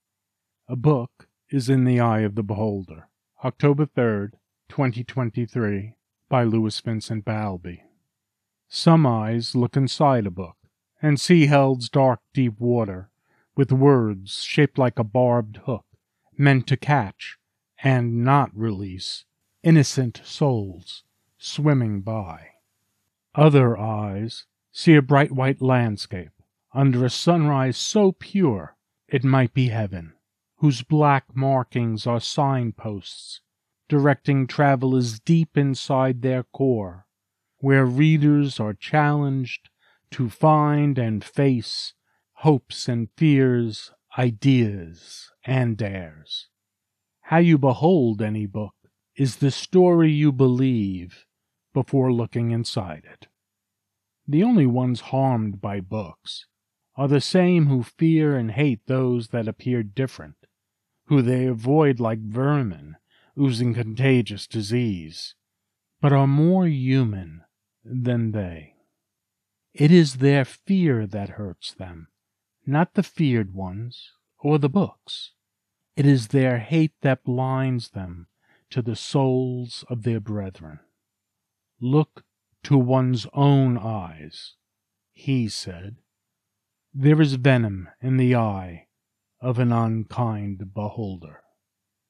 A Book Is in the Eye of the Beholder Poem